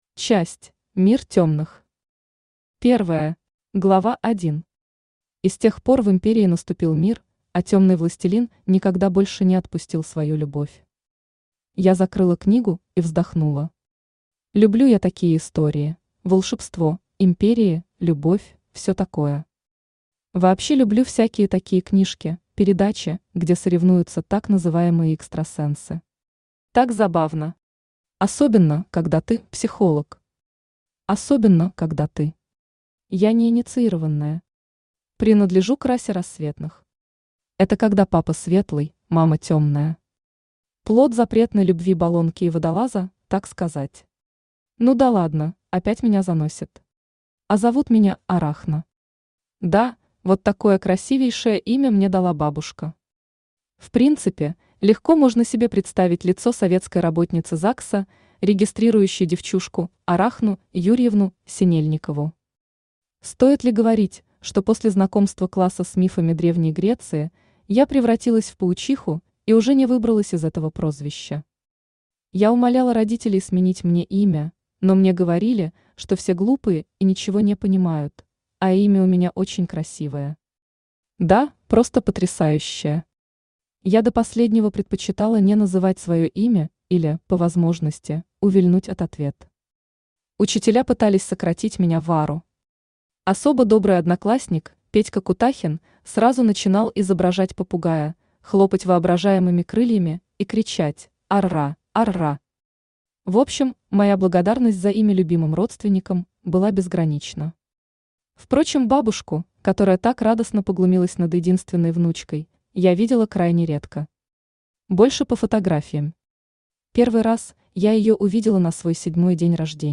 Аудиокнига И так бывает тоже | Библиотека аудиокниг
Aудиокнига И так бывает тоже Автор Анна Сергеевна Кубанцева Читает аудиокнигу Авточтец ЛитРес.